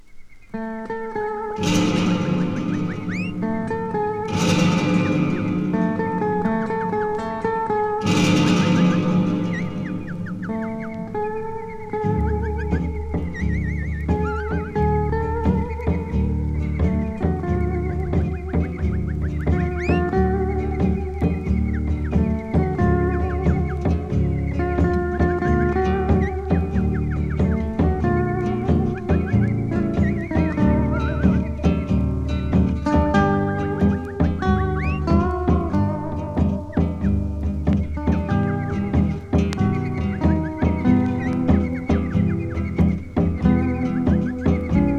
World, Exotic, Hawaii　UK　12inchレコード　33rpm　Stereo